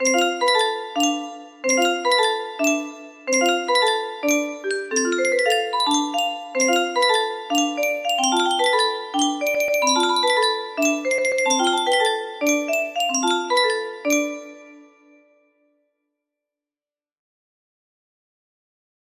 dara dara da music box melody